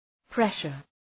Προφορά
{‘preʃər}